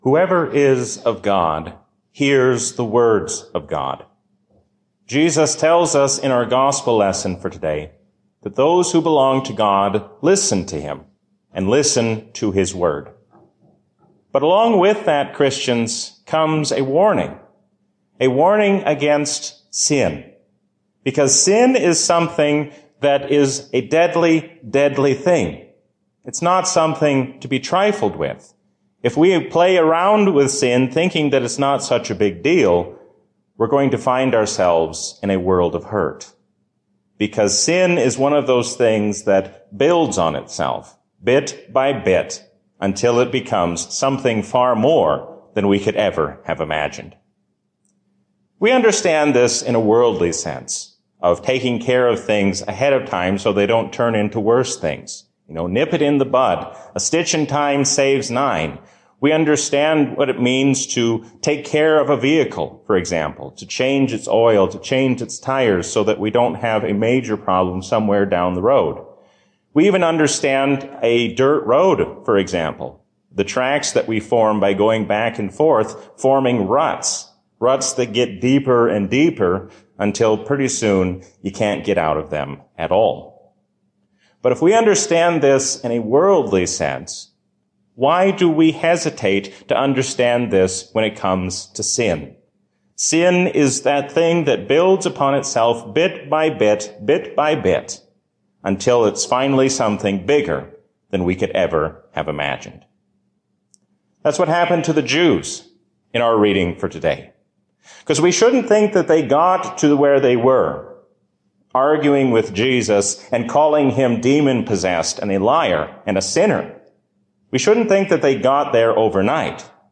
A sermon from the season "Lent 2020." Jesus was tempted in every way just like we are, but He passed the test.